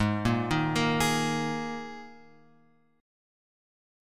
G# Suspended 2nd
G#sus2 chord {4 1 1 3 x 4} chord